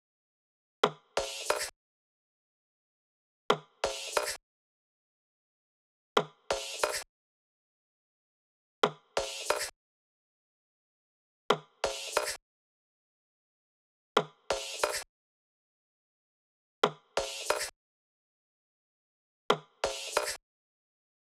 AV_Cribs_Percs_90bpm.wav